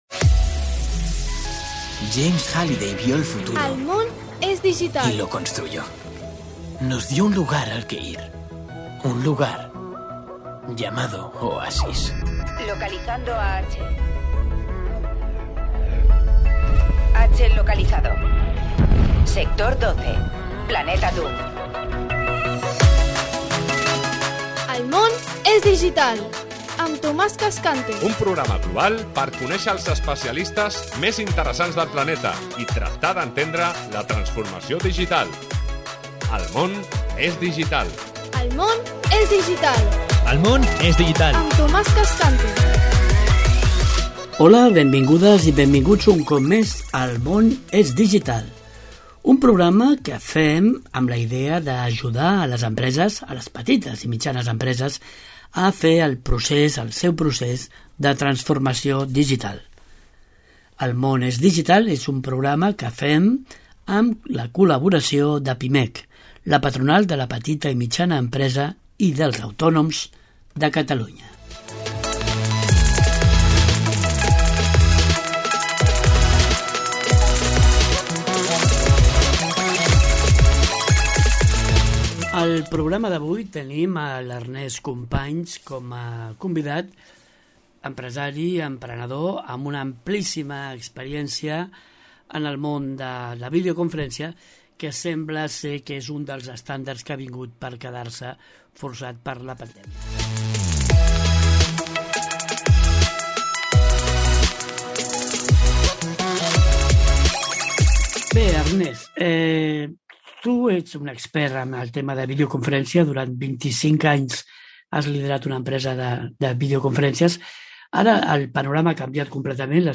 Cada setmana ens apropem a aquesta nova realitat a través de les entrevistes i les tertúlies amb destacats especialistes, directius, emprenedors i usuaris de les noves tecnologies.